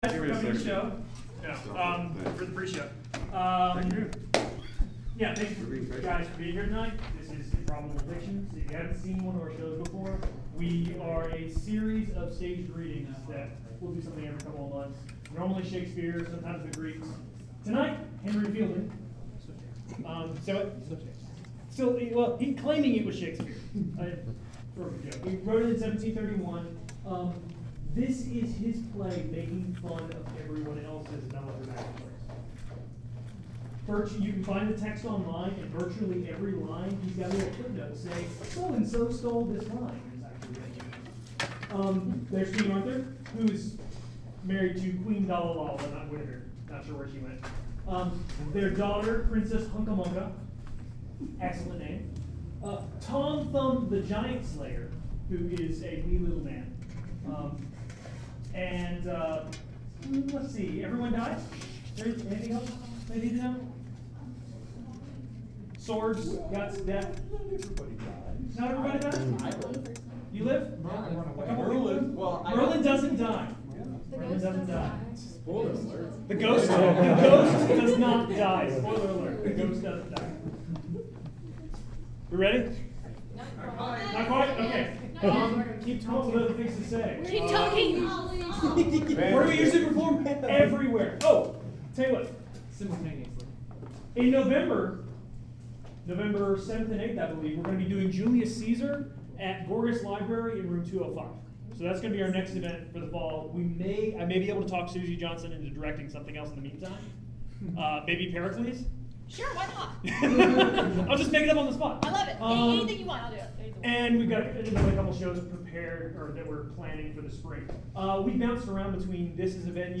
If you missed our staged reading this week, fret not!
Thanks again to all the actors and audience members!